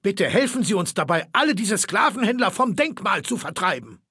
Datei:Maleold01 ms06 greeting 0005cbc4.ogg
Fallout 3: Audiodialoge